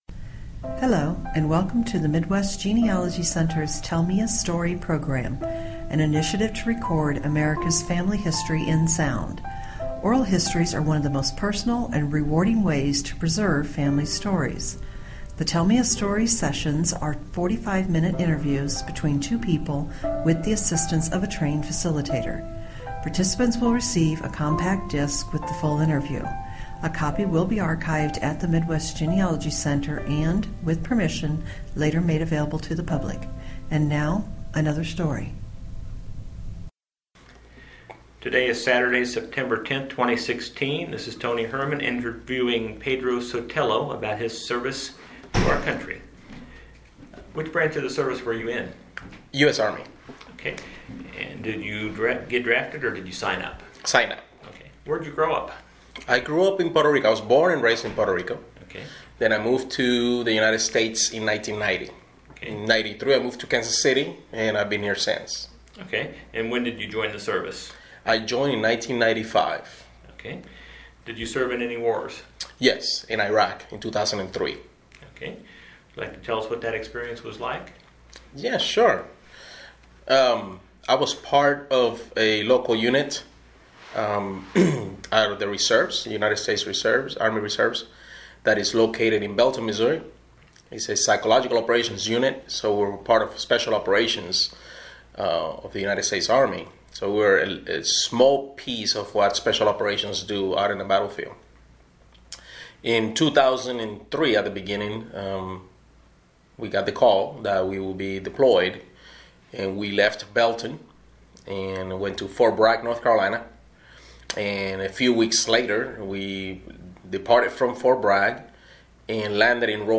Oral history